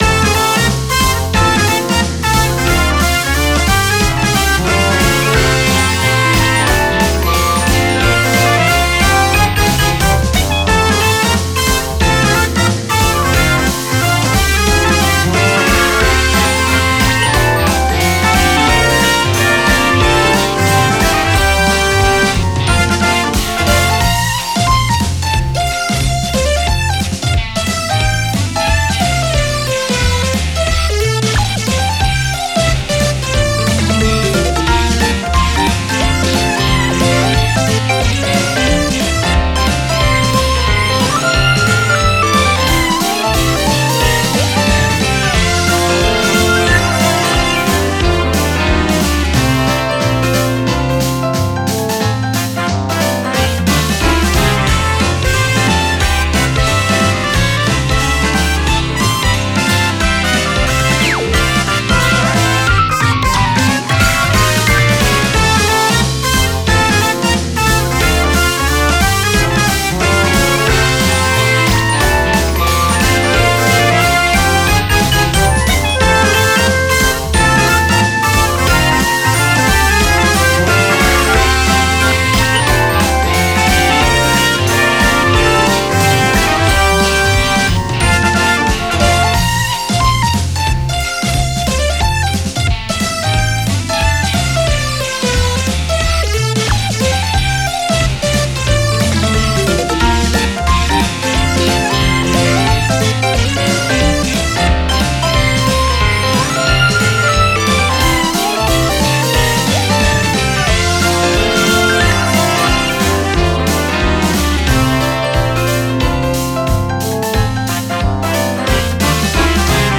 明るい雰囲気の曲です。
楽しそうです
タグ あかるい